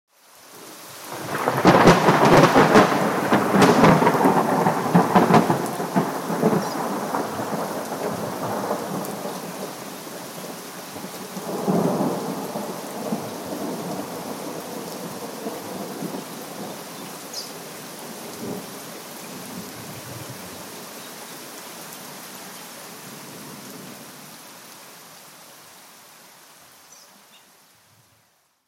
دانلود آهنگ رعدو برق 43 از افکت صوتی طبیعت و محیط
دانلود صدای رعدو برق 43 از ساعد نیوز با لینک مستقیم و کیفیت بالا
جلوه های صوتی